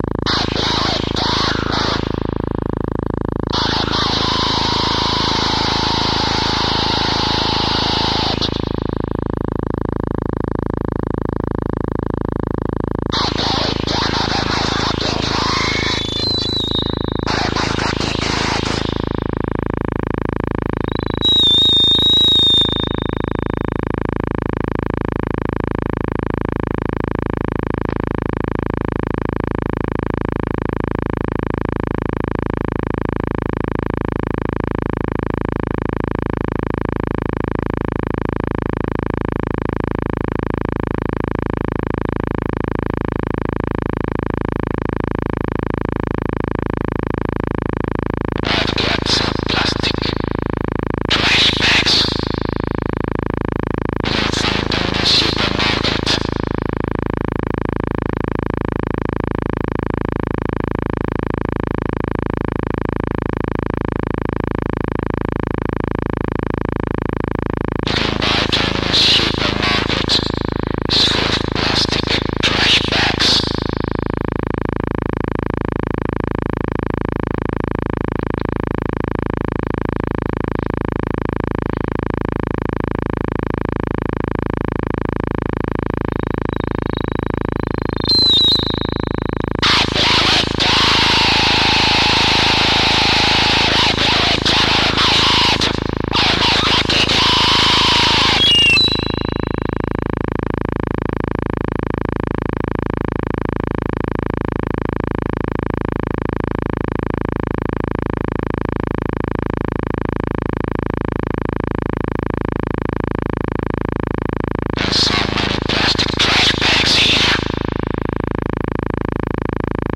Recorded March 1997 in Sassuolo, Italy
raw, improvised
With its haunting vocalisations and abrasive soundscapes
• Genre: Death Industrial / Power Electronics